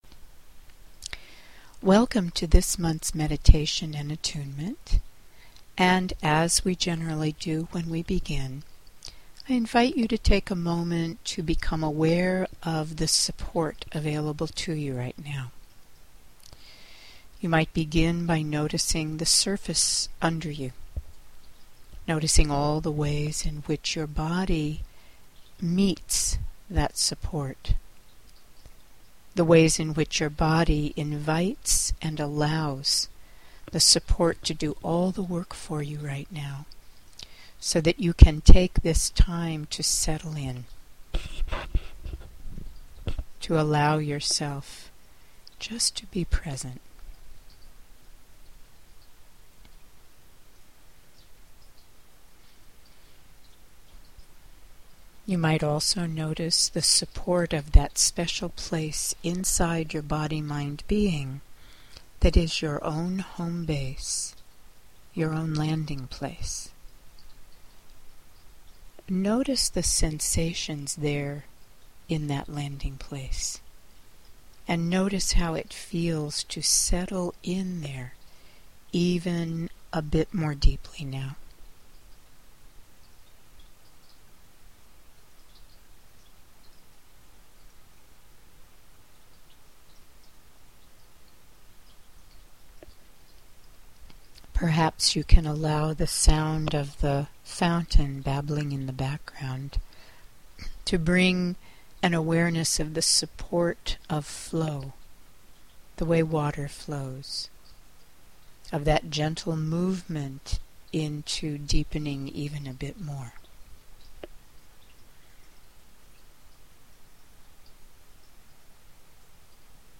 Here’s the audio version of the meditation…